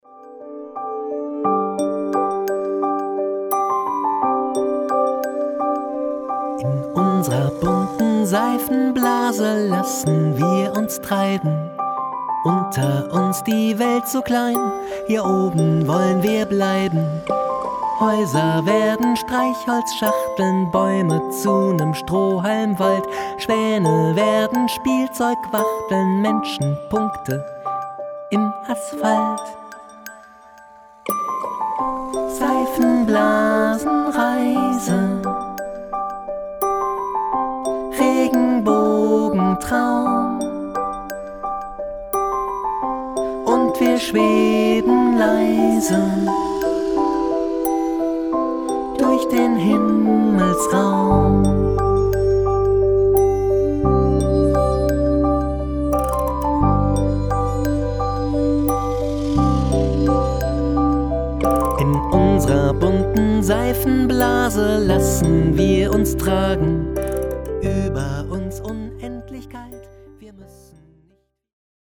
Roboter, Wecker, Luftpumpe und Weinglas
Manche Gastkünstler sind zwar nicht lebendig, aber sie klingen trotzdem toll!